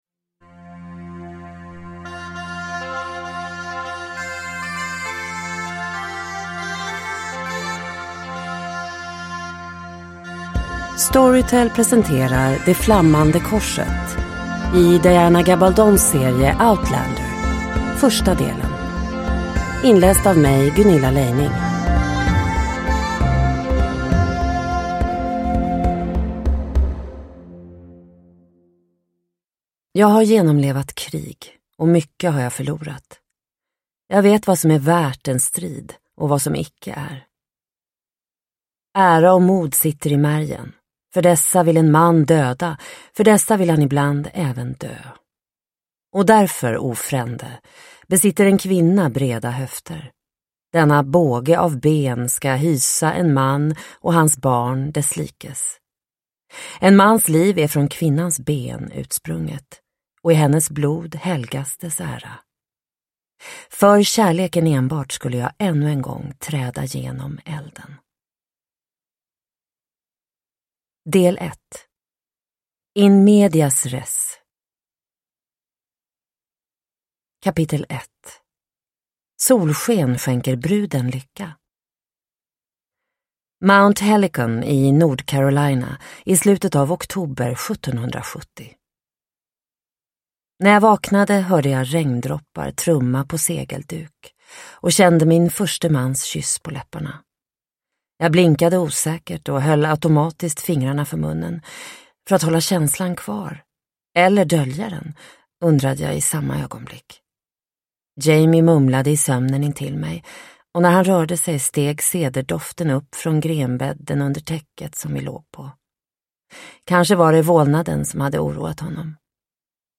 Det flammande korset - del 1 – Ljudbok – Laddas ner